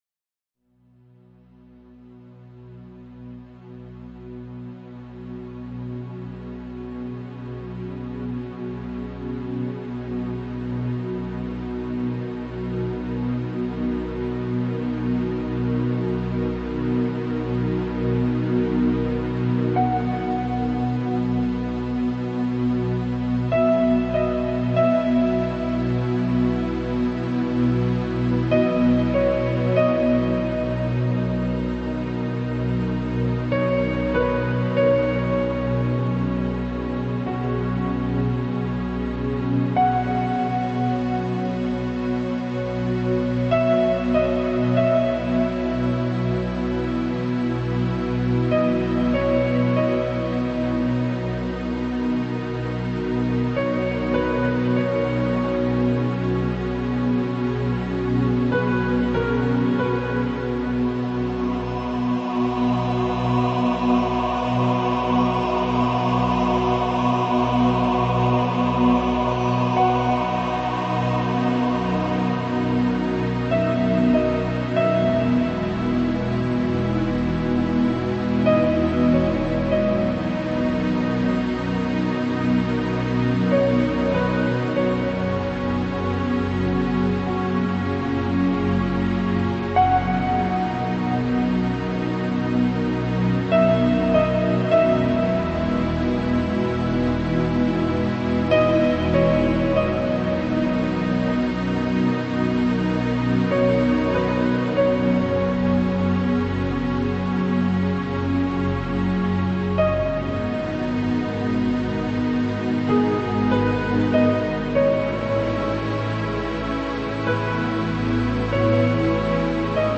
描绘乡村的静夜